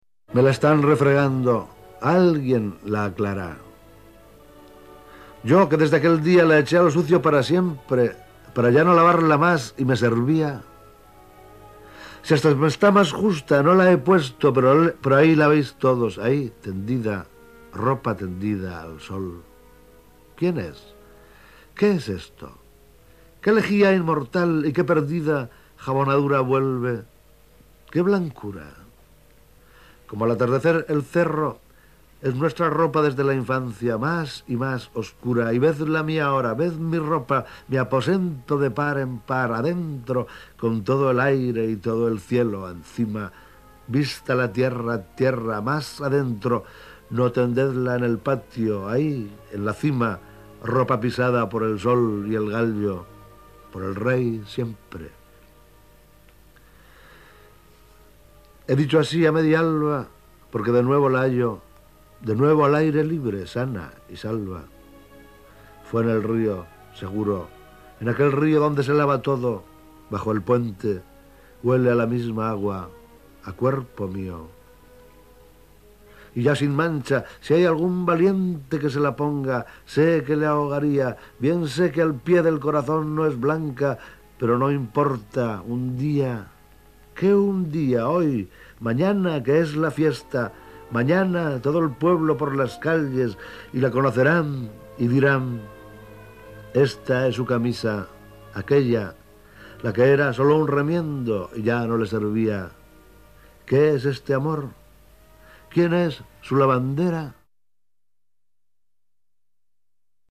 Autor del audio: el propio autor